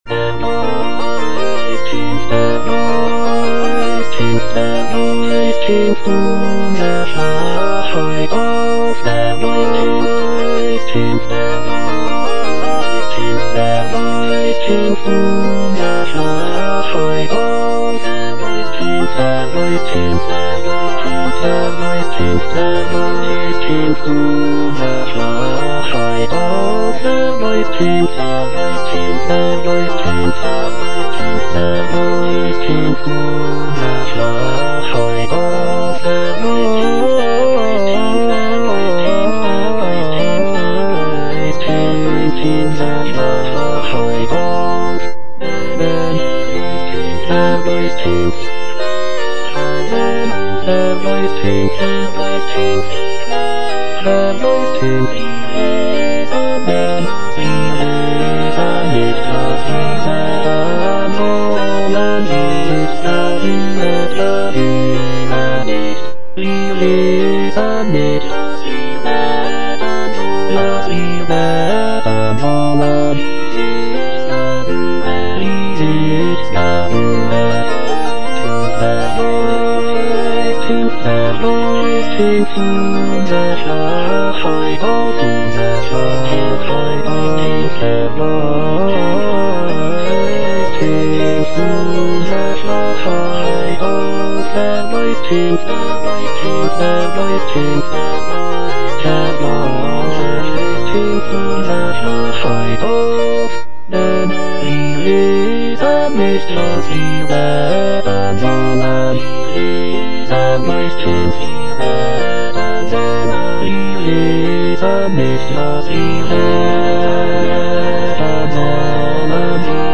J.S. BACH - DER GEIST HILFT UNSER SCHWACHHEIT AUF BWV226 Der Geist hilft unser Schwachheit auf (chorus II) - Bass (Emphasised voice and other voices) Ads stop: auto-stop